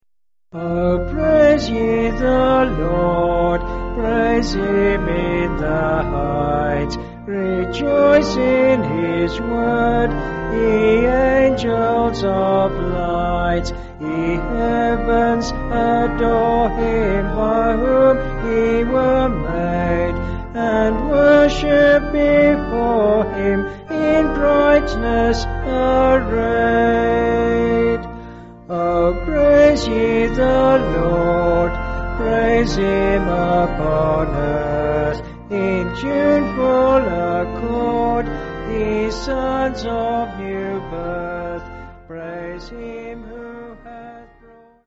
(BH)   4/Bb
Vocals and Organ